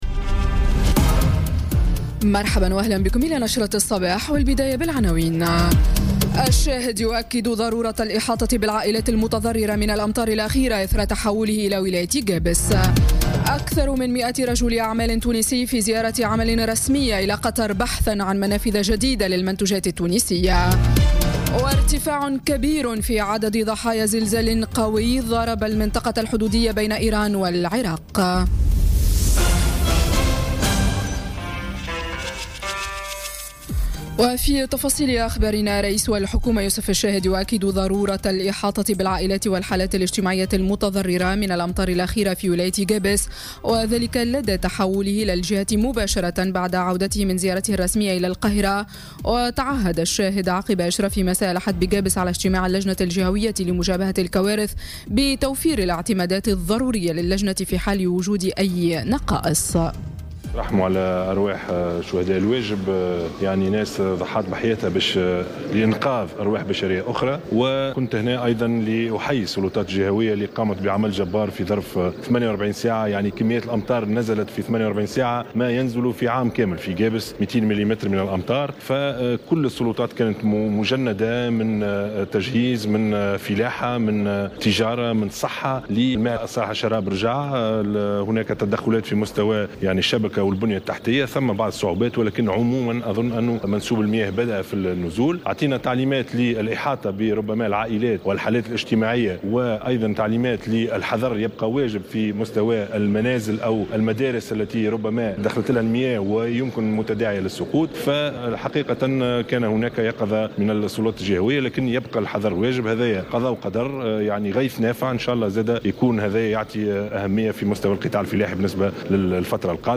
نشرة أخبار السابعة صباحا ليوم الإثنين 13 نوفمبر 2017